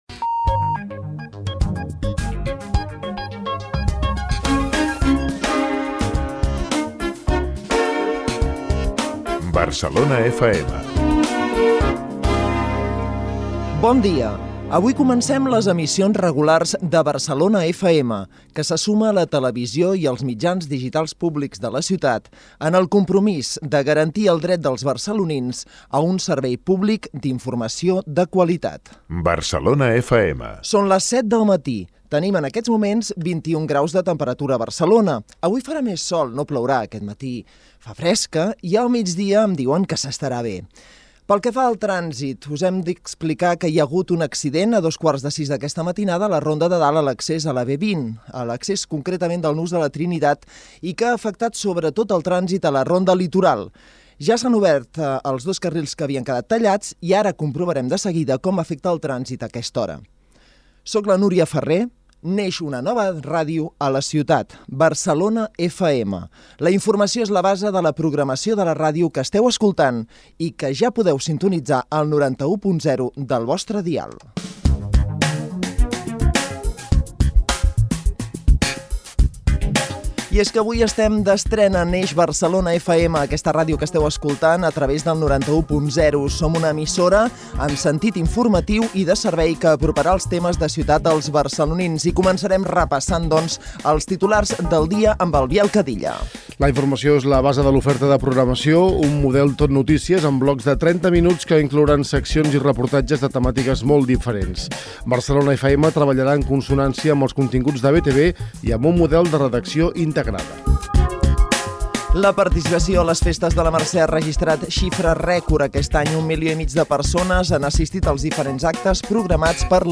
Indicatiu i primeres paraules en el moment que l'emissora municipal va adoptar el nom de Barcelona FM , indicatiu, hora, estat del temps, estat del trànsit. Neix Barcelona FM, festes de la Mercè, un any del nou Mercat dels Encants, etc.
Informatiu